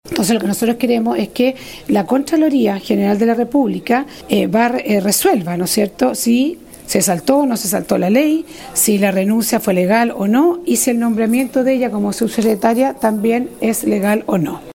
La diputada de Renovación Nacional, Ximena Ossandón, indicó que la alcaldesa “se saltó” el presentar las causas justificadas para renunciar.